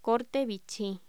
Locución: Corte vichy
voz